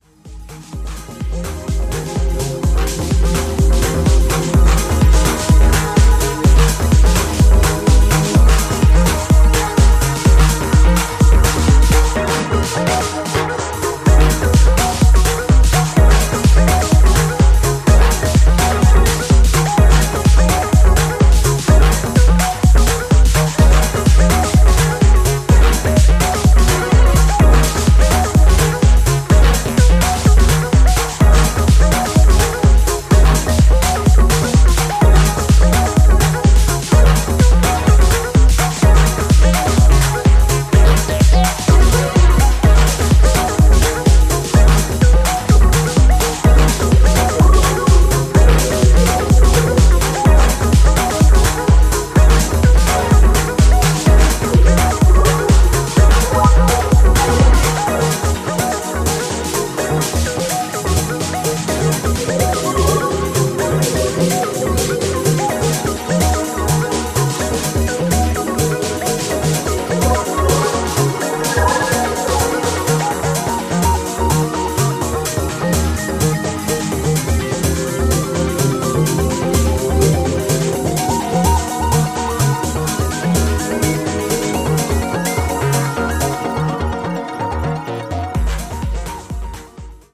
ファンキーにスウィングしたクラシカルな909ビート、ブレイクで現れる美しいメロディが心に響く
デトロイト古参ファンから新規ファンまで幅広く届いて欲しい、オールドスクール愛溢れる作品です。